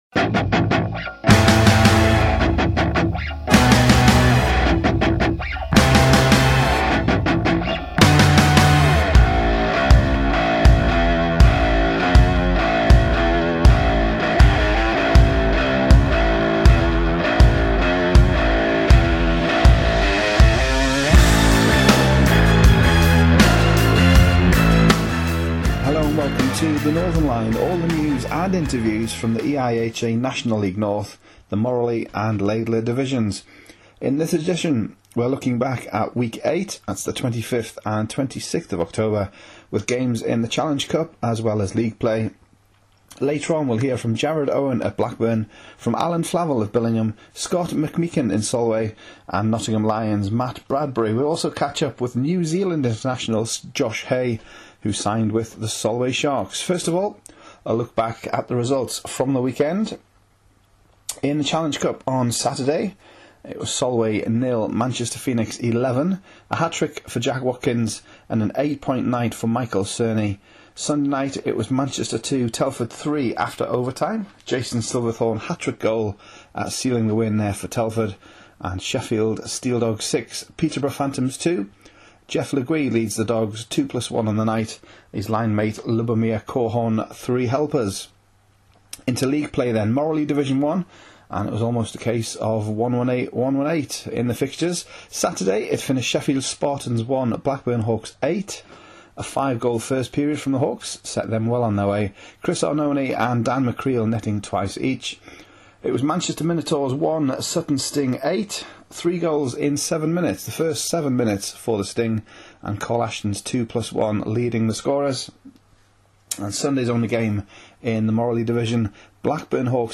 This Is The Northern Line - podcast review of Week 8 action in NIHL North